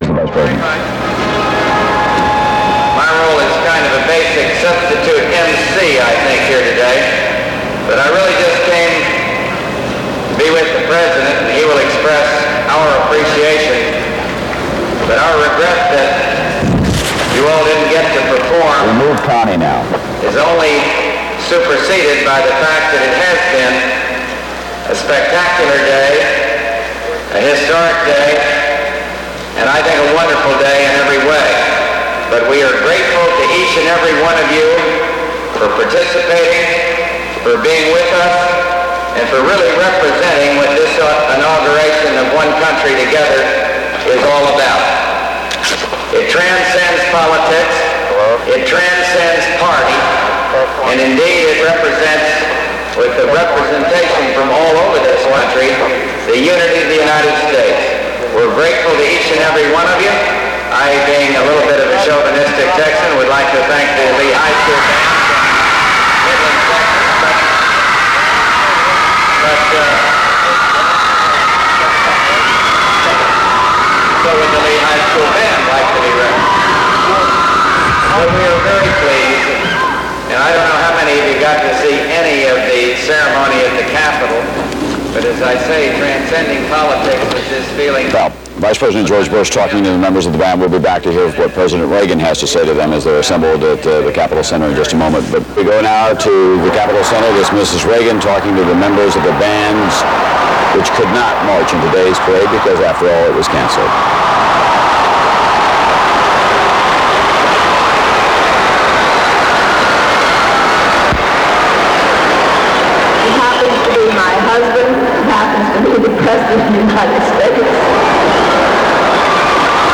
Ronald Reagan, George Bush, and Nancy Reagan address thousands of high school bandsmen who were disappointed by the cancellation of the Inaugural parade due sub-zero weather in Washington, D.C. Includes commentary from an unidentified reporter.